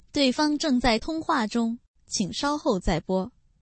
avchat_peer_busy.mp3